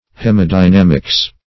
Search Result for " hemadynamics" : The Collaborative International Dictionary of English v.0.48: Hemadynamics \He`ma*dy*nam"ics\, n. [Hema- + dynamics.]